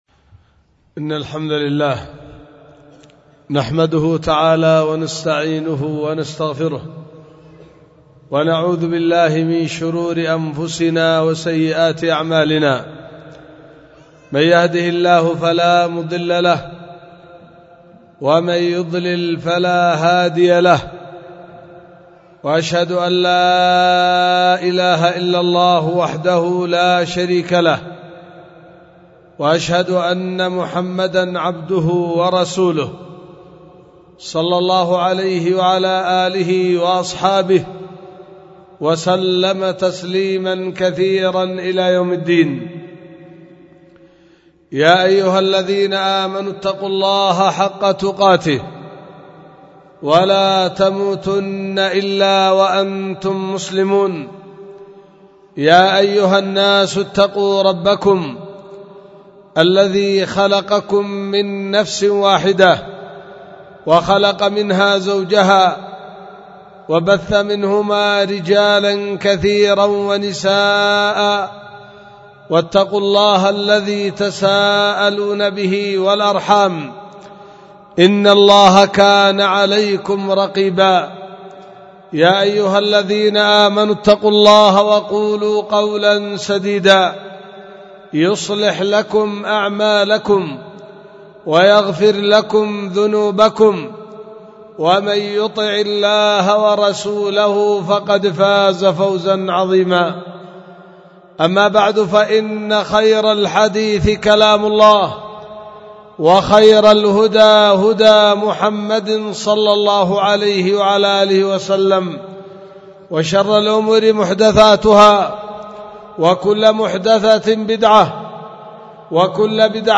خطبة ألقيت في 17 ربيع الآخر 1444 هجرية في دار الحديث بوادي بنا – السدة – إب – اليمن